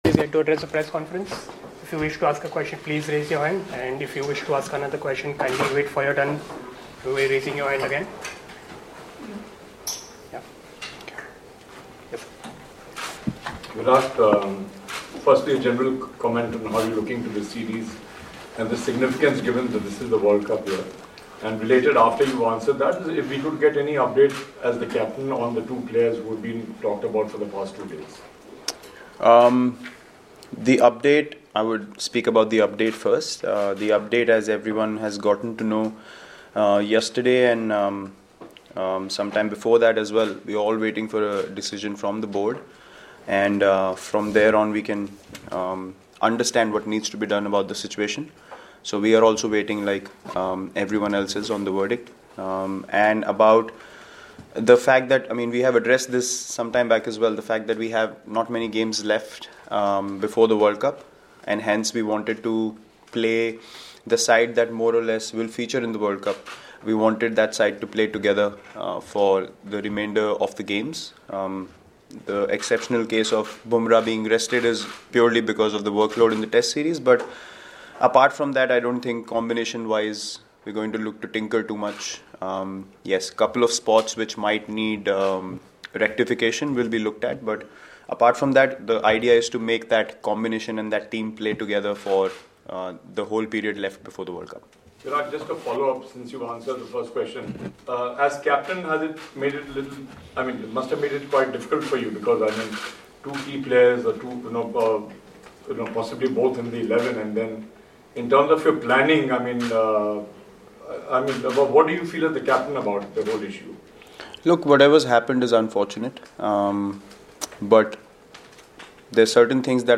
Virat Kohli speaks with the media ahead of the first ODI at the SCG
Virat Kohli, Captain, Indian Cricket Team. He interacted with the media in Sydney on Friday, January 11 ahead of the first ODI at the SCG.